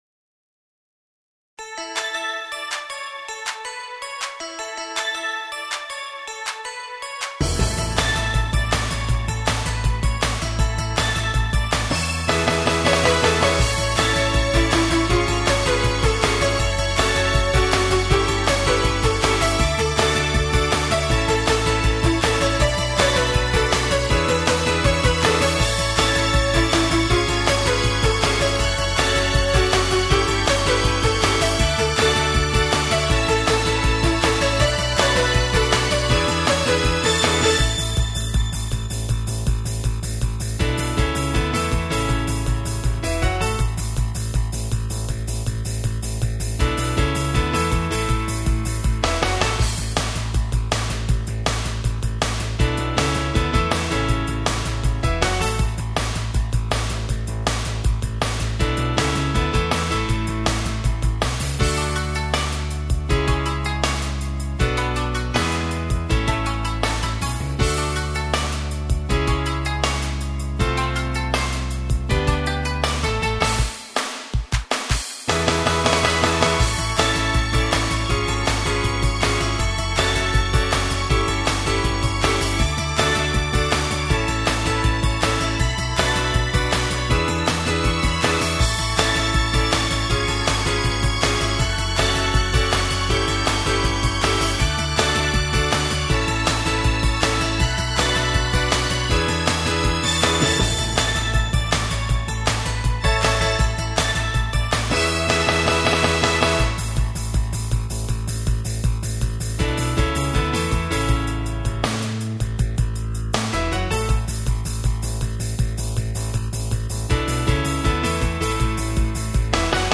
カラオケ(MP3)